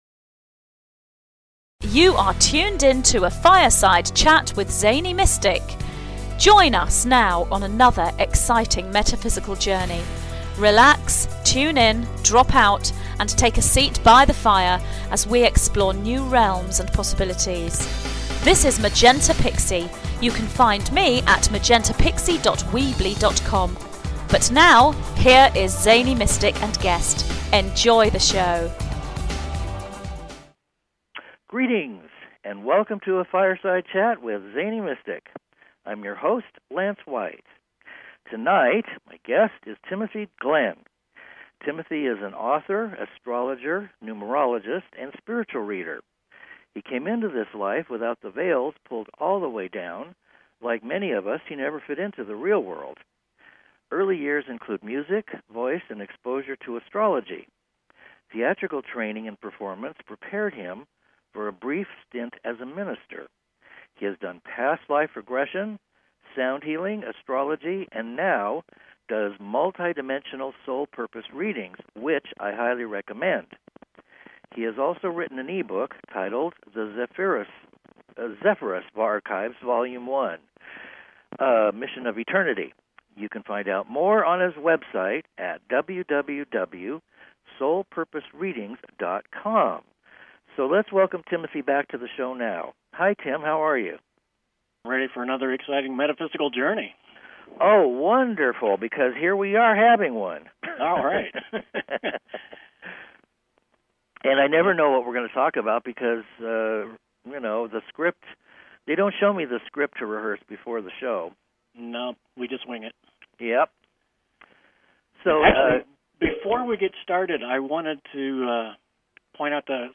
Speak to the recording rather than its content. A humorous and eclectic mix as we peek beyond the Matrix.